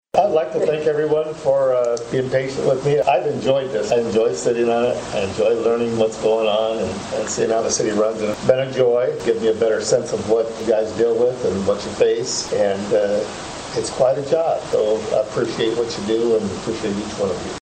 Moyer says he has enjoyed his time on the Commission.